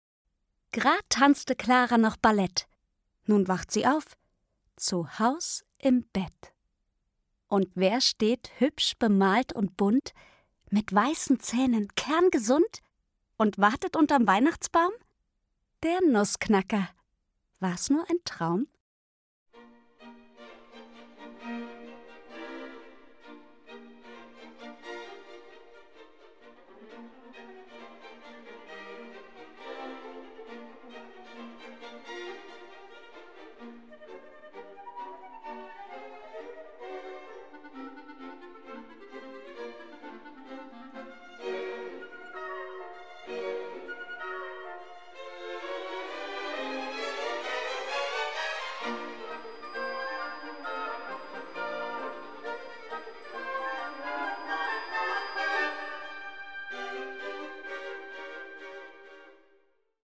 Meine Musikmärchen - Nussknacker und Mausekönig Märchen, Reime und Musik.
Josefine Preuß (Sprecher)